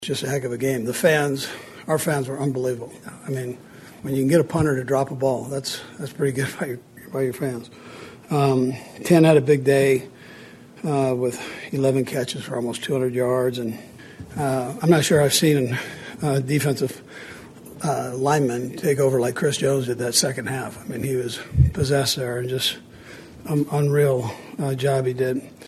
Coach Andy Reid said everyone including the fans had a hand in the win.